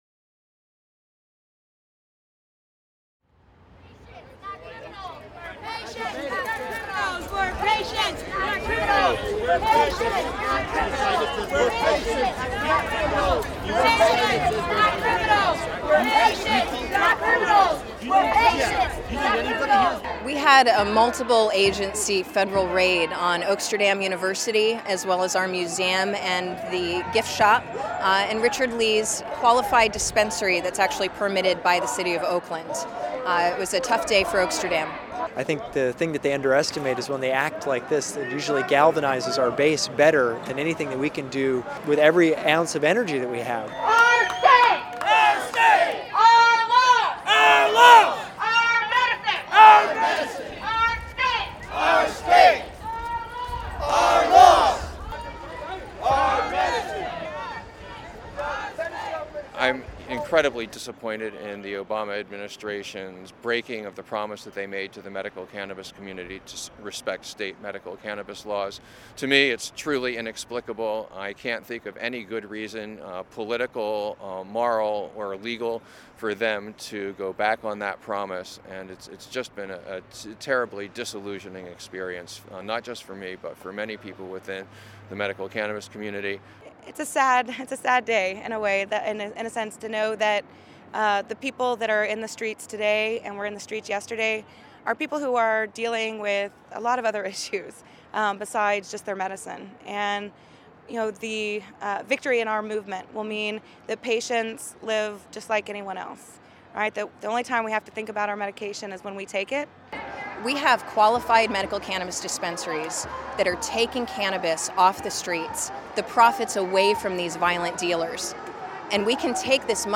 On April 3, several hundred people gathered at a rally at the San Francisco City Hall to protest the federal government's crackdown on medical marijuana dispensaries.
On the City Hall steps, six of the eleven San Francisco Supervisors spoke out against the federal crackdown, as did representatives of the city council, the city attorney's office and the California State Legislature.
Later in the day, protestors marched to the Federal Building a few blocks away and chanted "DEA go away" to a line of federal officers guarding the entrance.